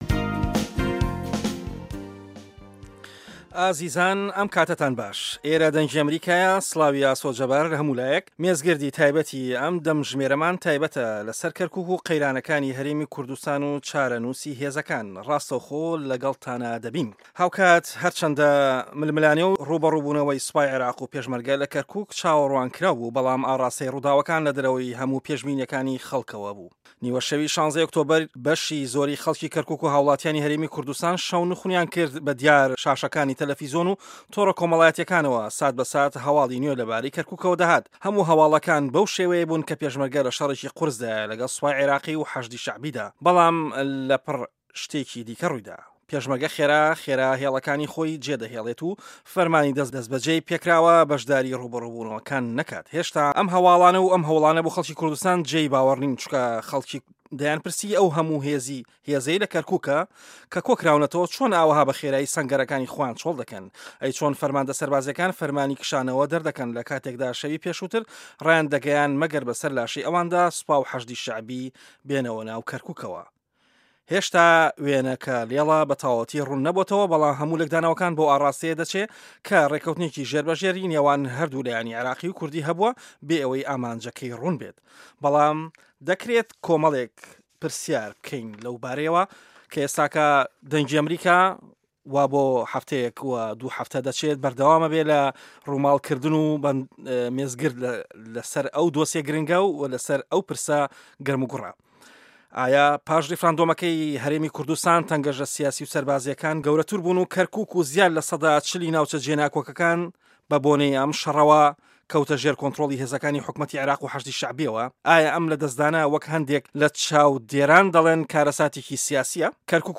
مێزگرد: که‌رکوک و قه‌یرانه‌کان له‌ گه‌ڵ به‌غدا